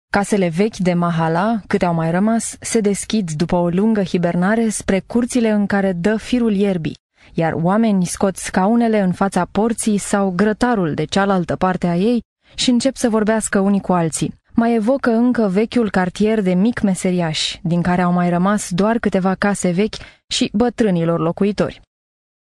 rumänische Profi Sprecherin für Werbung, TV, Industrie, Radio etc. Professional female voice over talent from Romania
Sprechprobe: eLearning (Muttersprache):
Professional female voice over talent romanian